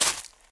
footsteps
decorative-grass-04.ogg